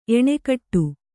♪ eṇekaṭṭu